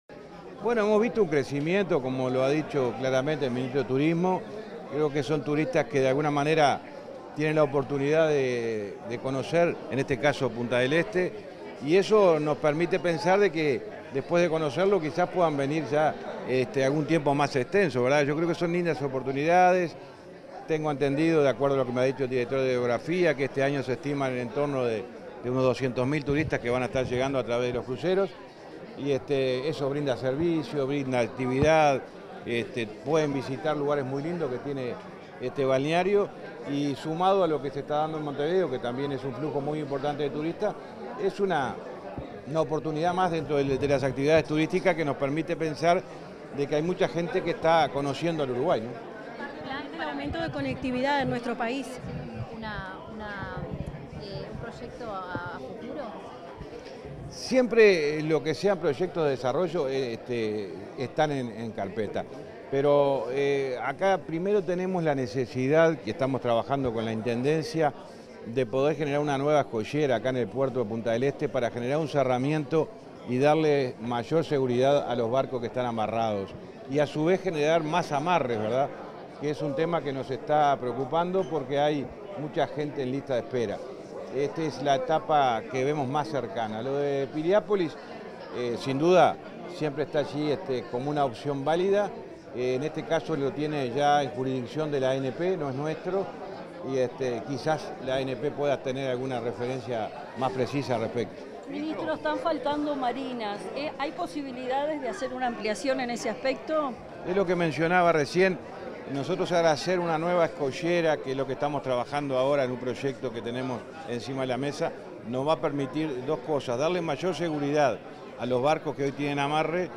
Declaraciones del ministro de Transporte y Obras Públicas, José Luis Falero
Declaraciones del ministro de Transporte y Obras Públicas, José Luis Falero 09/01/2024 Compartir Facebook X Copiar enlace WhatsApp LinkedIn Tras el lanzamiento de la temporada de cruceros 2023-2024, el ministro de Transporte y Obras Públicas, José Luis Falero, realizó declaraciones a la prensa.